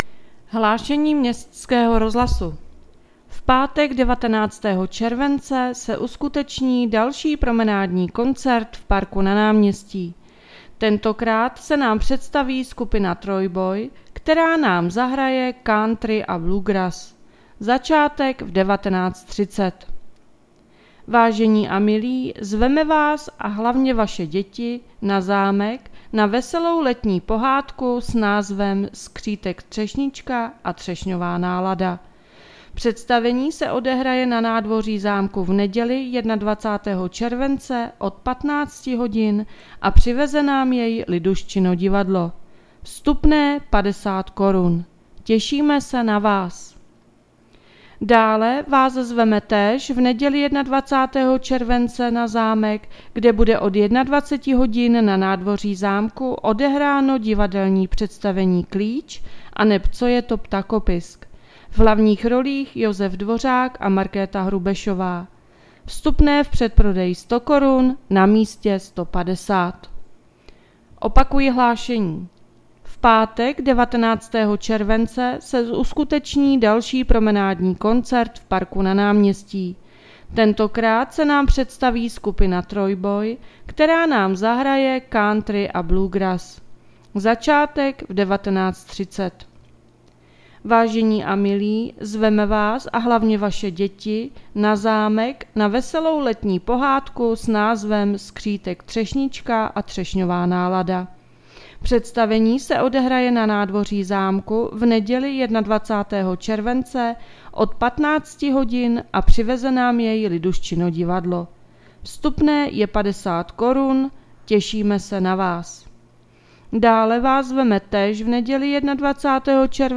Hlášení městského rozhlasu 19.7.2024